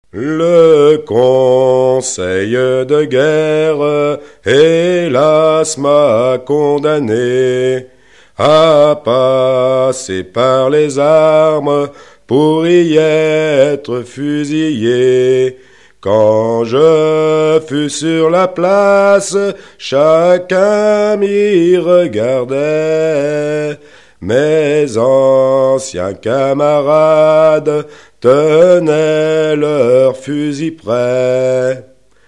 Genre strophique
Enquête Arexcpo en Vendée
Pièce musicale inédite